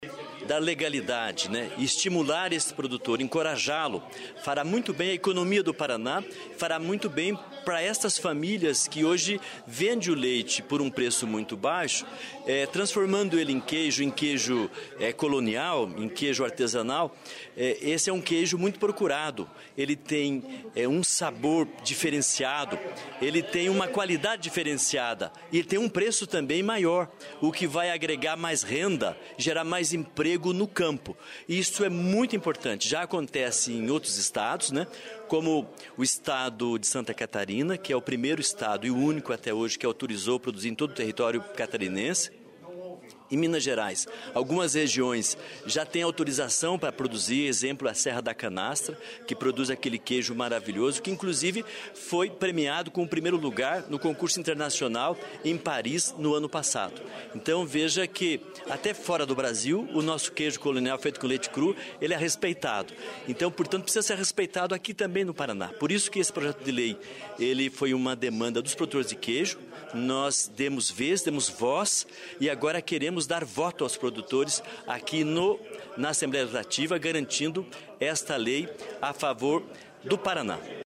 Ouça a entrevista do parlamentar.